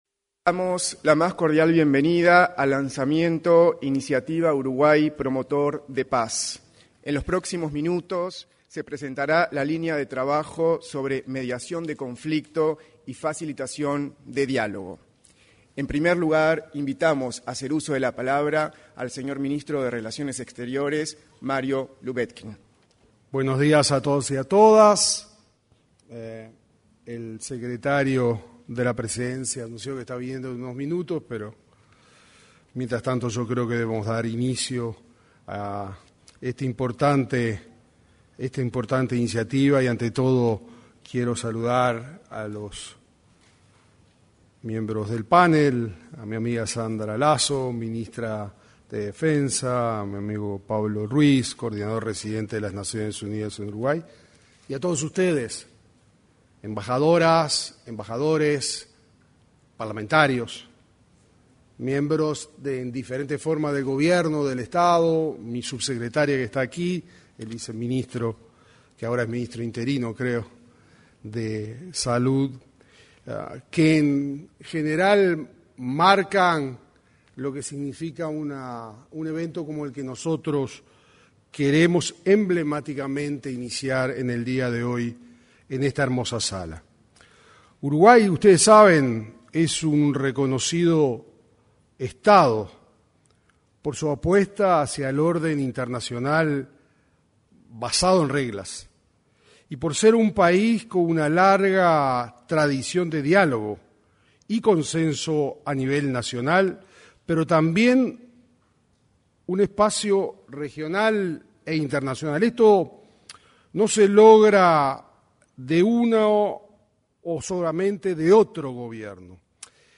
Lanzamiento de la iniciativa Uruguay Promotor de Paz 27/11/2025 Compartir Facebook X Copiar enlace WhatsApp LinkedIn En el auditorio de la Torre Ejecutiva anexa, se realizó el lanzamiento de la iniciativa Uruguay Promotor de Paz. En la oportunidad, se expresaron el ministro de Relaciones Exteriores, Mario Lubetkin, el secretario de la Presidencia de la República, Alejandro Sánchez, el coordinador residente de las Naciones Unidas en Uruguay, Pablo Ruiz, y la ministra de Defensa Nacional, Sandra Lazo.